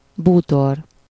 Ääntäminen
Synonyymit meuble Ääntäminen France: IPA: [mɔ.bi.lje] Haettu sana löytyi näillä lähdekielillä: ranska Käännös Ääninäyte 1. bútor Suku: m .